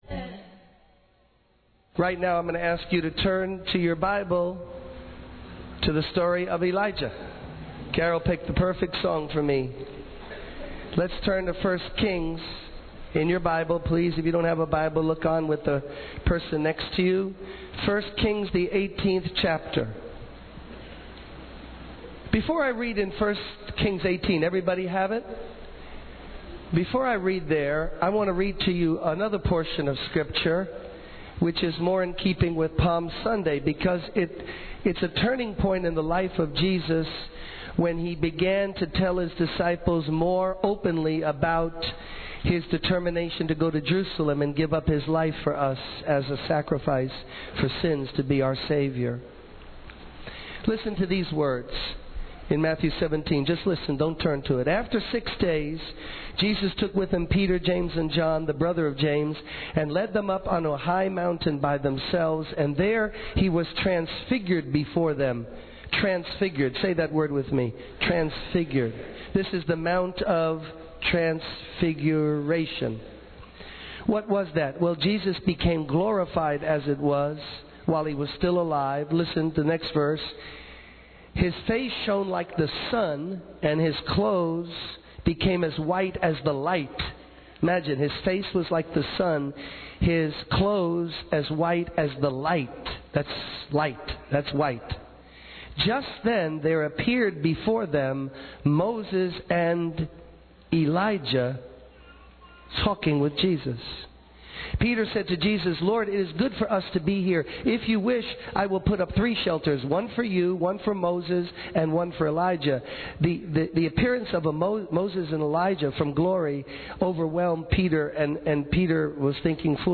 The speaker then reads a passage from Matthew 17, describing the transfiguration of Jesus on the mountain. The speaker emphasizes the significance of this event, highlighting how Jesus became glorified and appeared with Moses and Elijah.